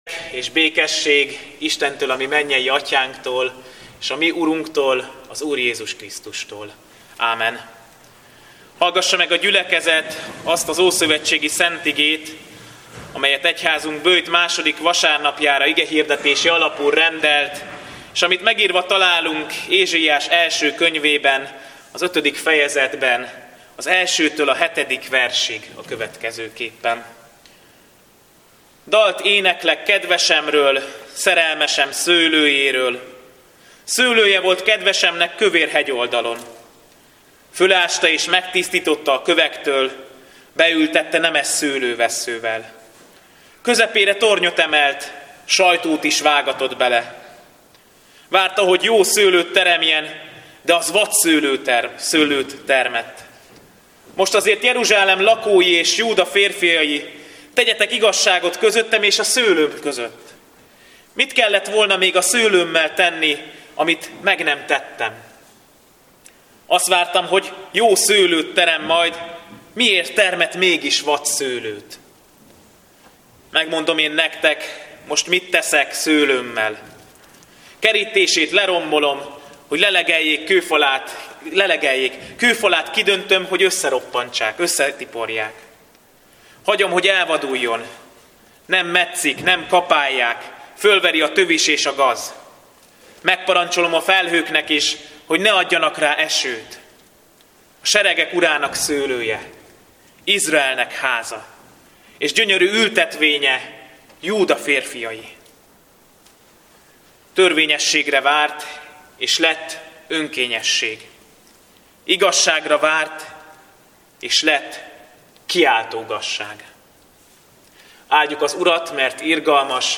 Böjt 2. vasárnapja - Emlékezz meg, Uram, irgalmadról és kegyelmedről, mert azok öröktől fogva vannak!
Szószékcser kapcsán a mai igét templomunkban